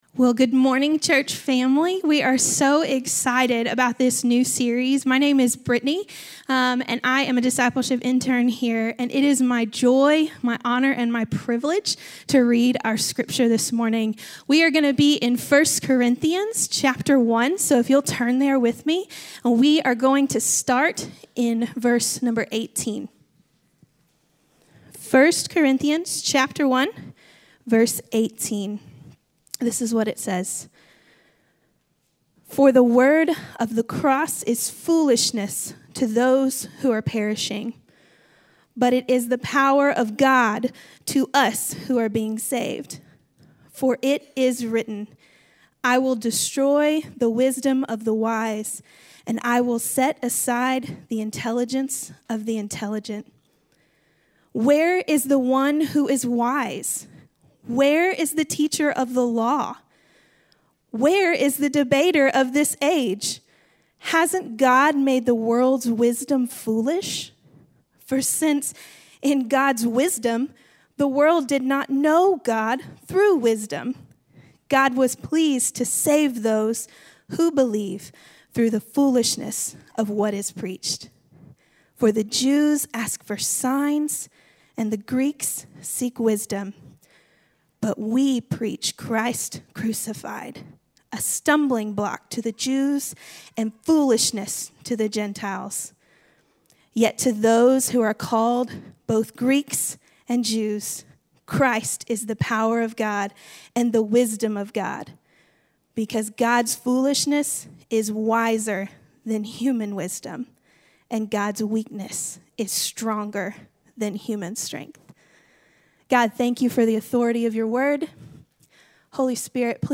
Finding Our Way - Sermon - Avenue South